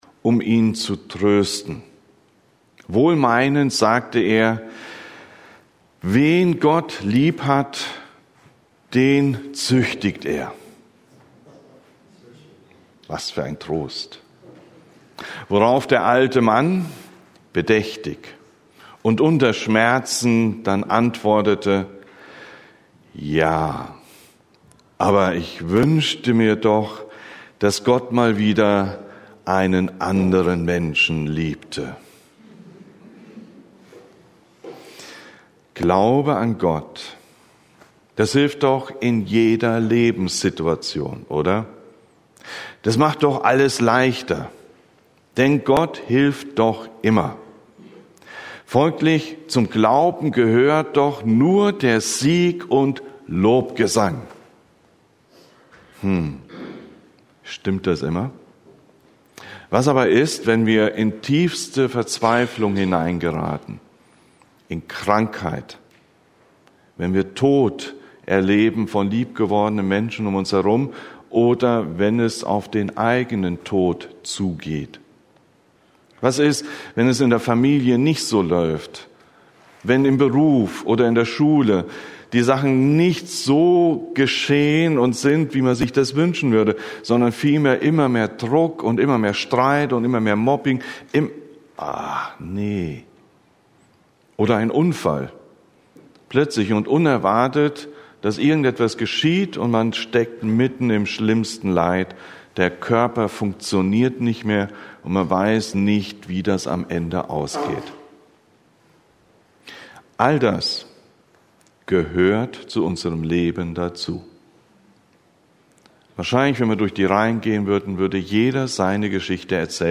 Das Leid so groß – Predigten: Gemeinschaftsgemeinde Untermünkheim